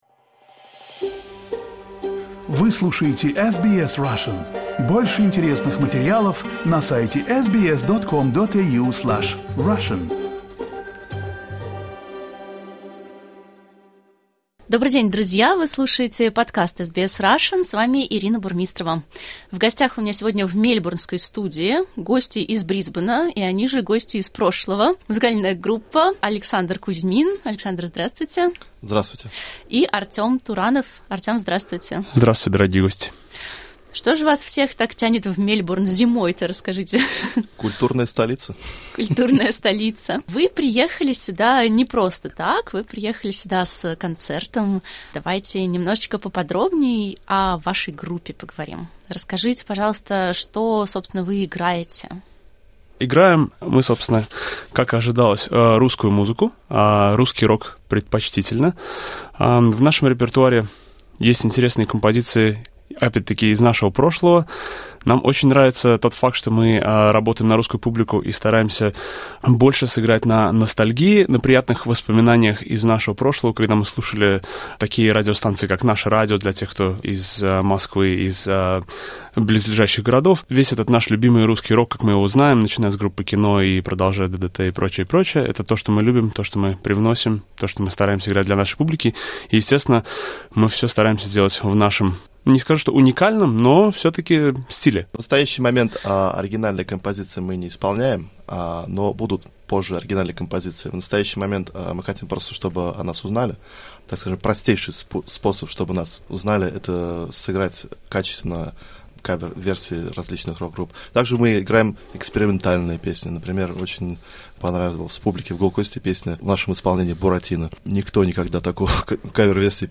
Группа "Гости из прошлого" в студии SBS.